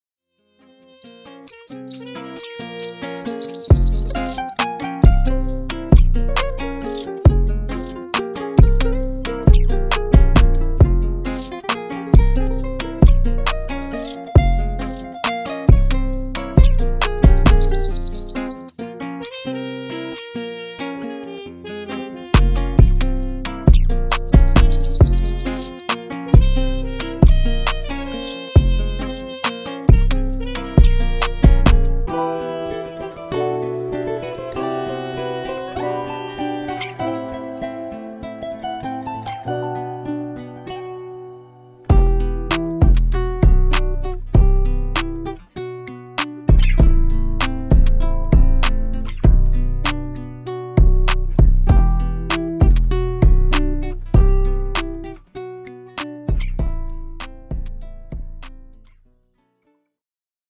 Unlock a World of Trap Rhythms and Latin melodies